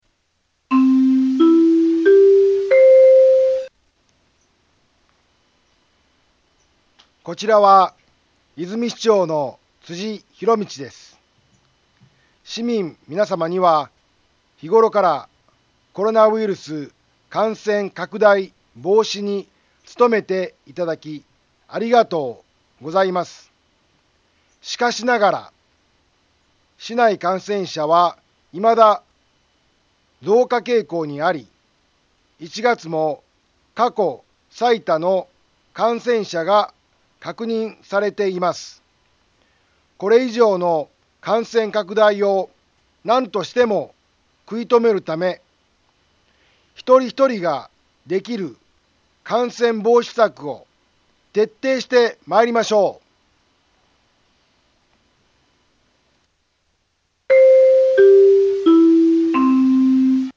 Back Home 災害情報 音声放送 再生 災害情報 カテゴリ：通常放送 住所：大阪府和泉市府中町２丁目７−５ インフォメーション：こちらは、和泉市長の辻ひろみちです。